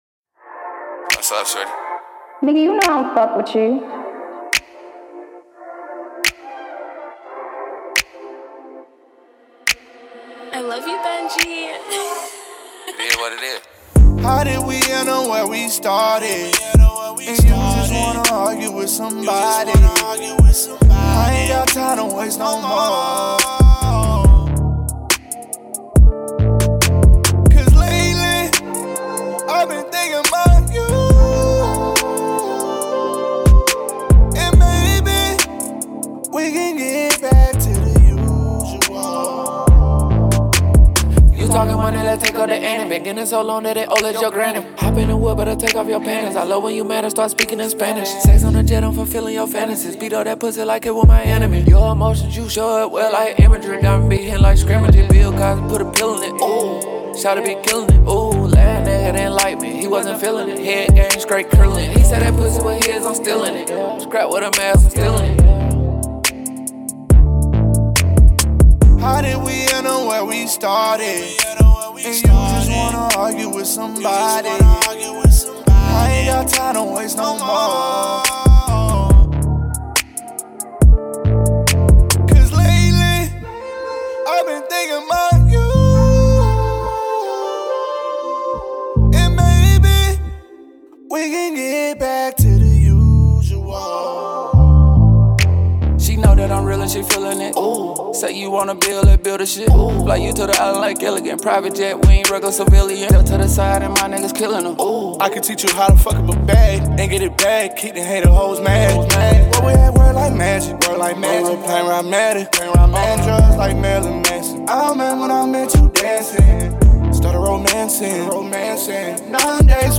Hiphop
The rising rap duo